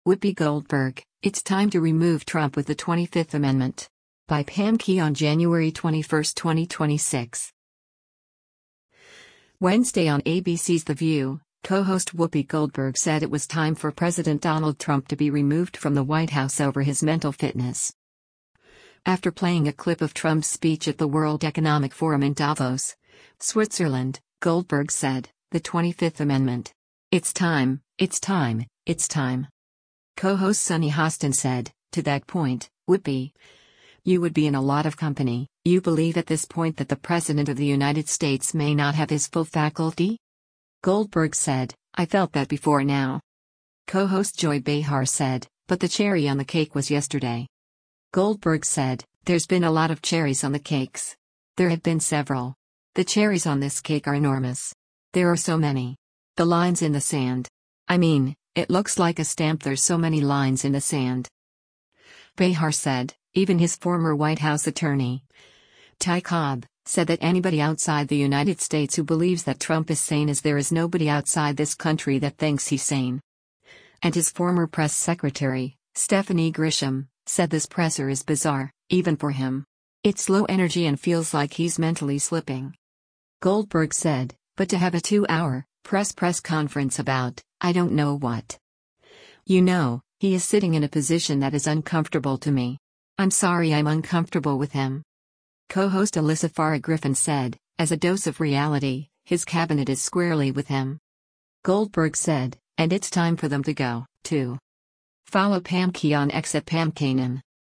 Wednesday on ABC’s “The View,” co-host Whoopi Goldberg said it was time for President Donald Trump to be removed from the White House over his mental fitness.